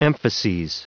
Prononciation du mot emphases en anglais (fichier audio)
Prononciation du mot : emphases